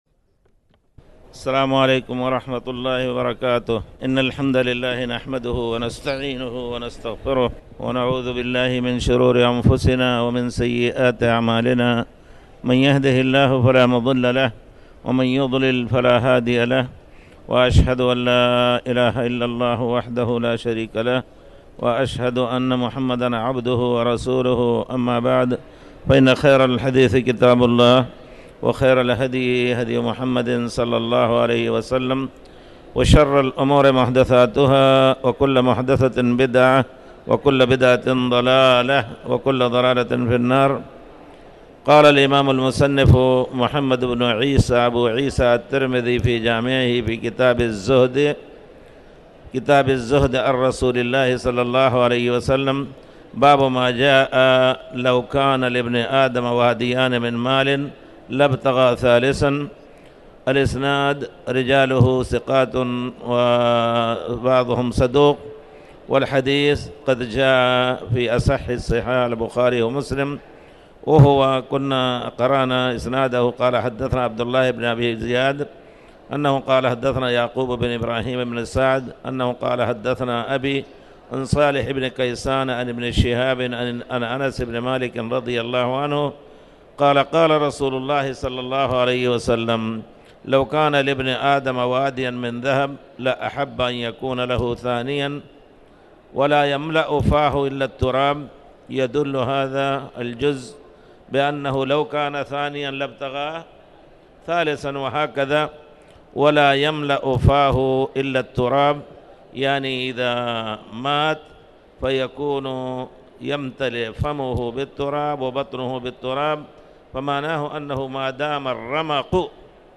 تاريخ النشر ١٠ جمادى الأولى ١٤٣٩ هـ المكان: المسجد الحرام الشيخ